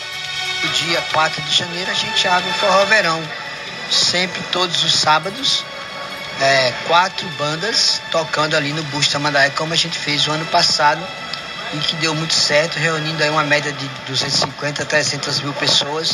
“Sempre todos os sábados, quatro bandas tocando no Busto de Tamandaré como a gente fez ano passado e que deu muito certo, reunindo aí uma média de 250/300 mil pessoas”, detalhou durante entrevista ao programa Arapuan Verdade.